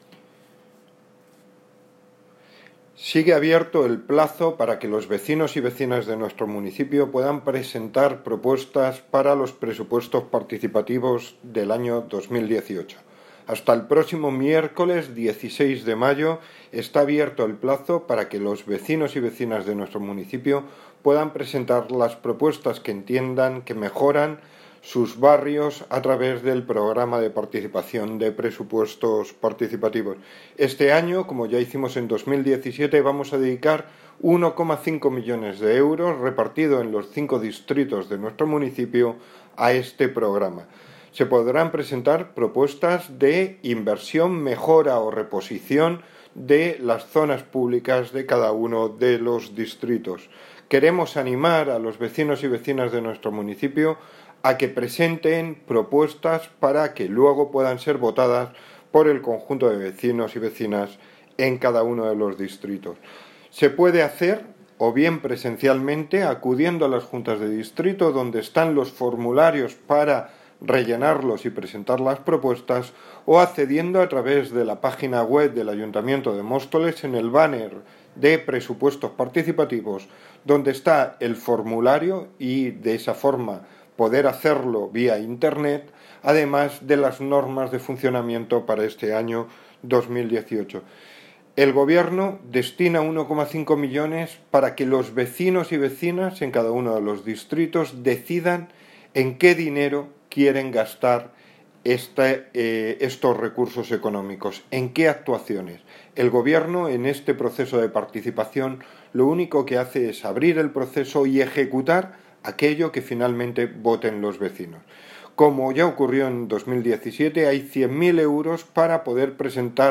Audio - Francisco Javier Gómez (Concejal de Hacienda, Transporte y Movilidad) Sobre Presupuestos Participativos de 2018